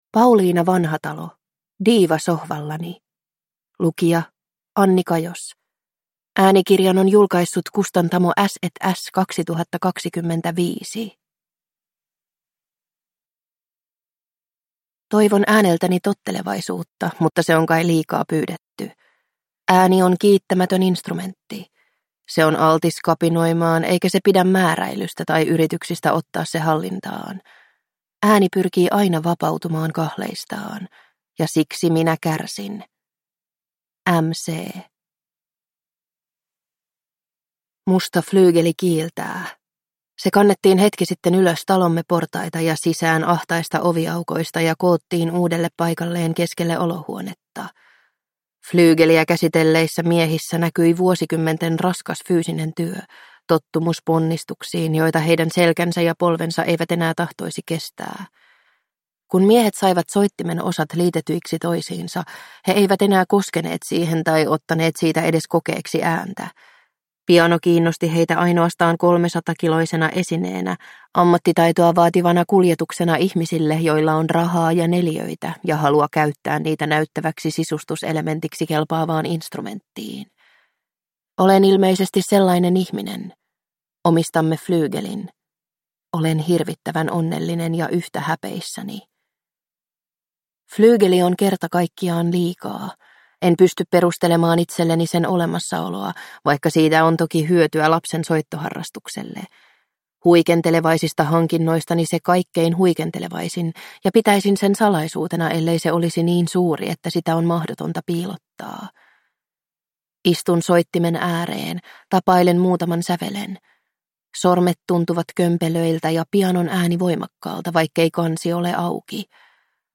Diiva sohvallani (ljudbok) av Pauliina Vanhatalo